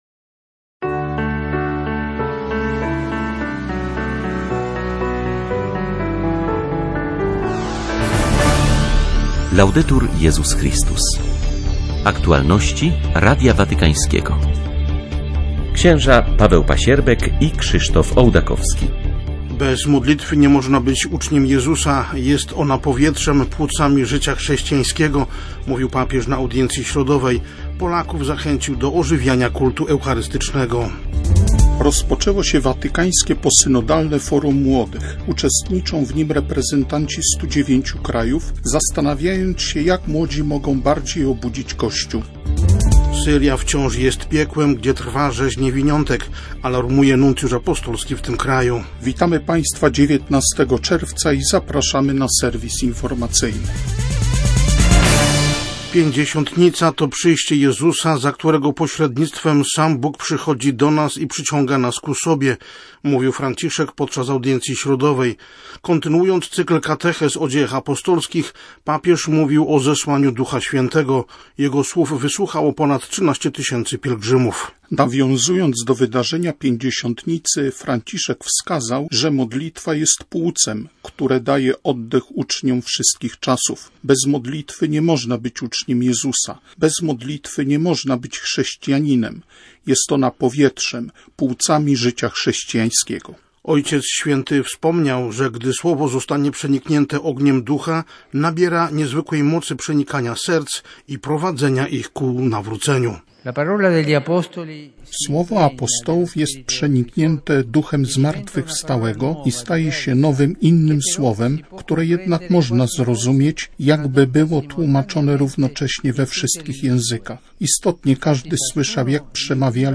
Wiadomości -